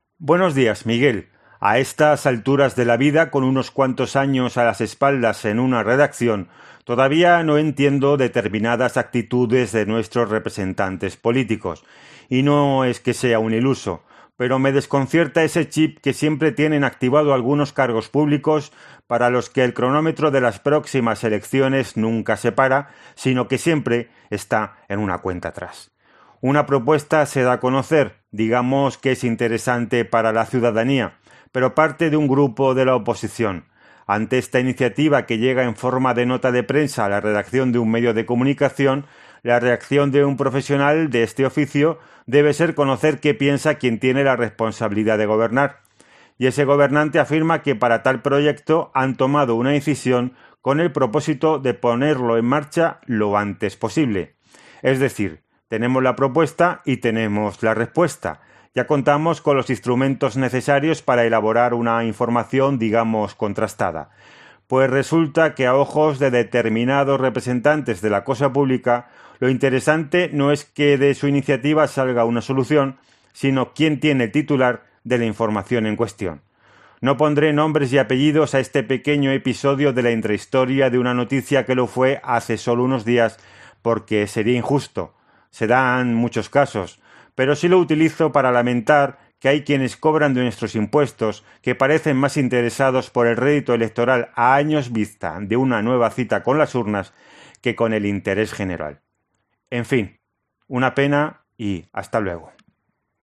OPINIÓN